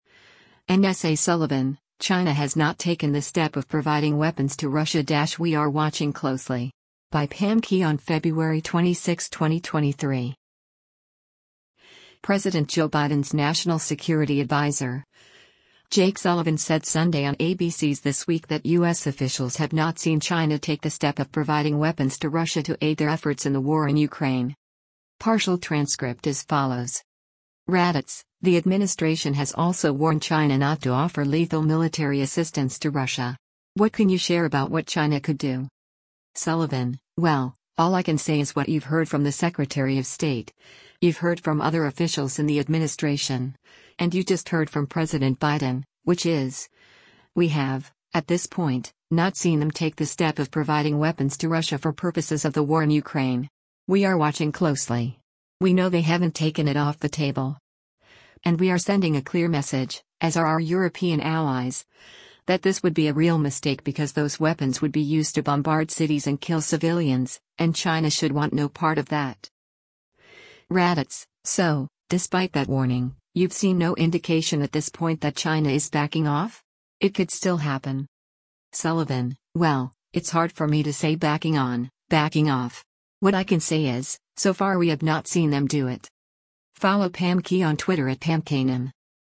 President Joe Biden’s national security adviser, Jake Sullivan said Sunday on ABC’s “This Week” that U.S. officials have not seen China “take the step of providing weapons to Russia” to aid their efforts in the war in Ukraine.